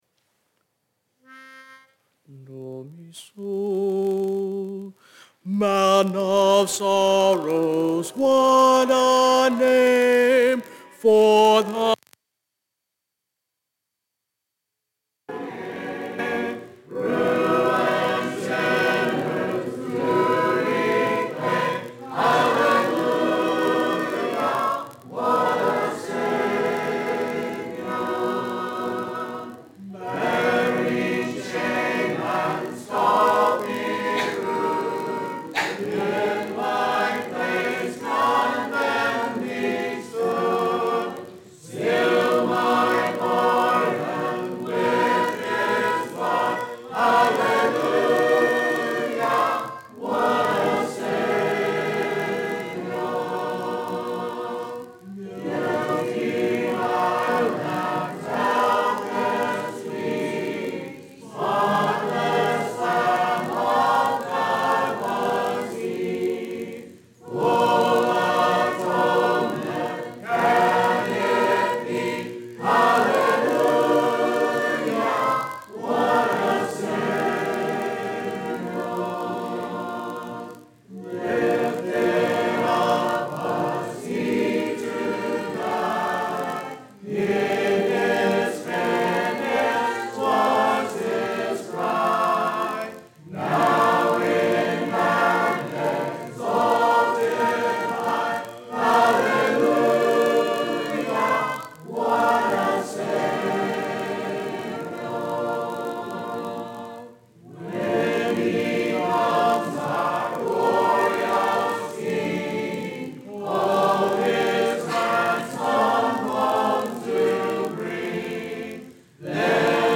Sun AM Bible Study 05.01.22